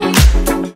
Похоже на synth brass с фильтром наподобие wah-wah.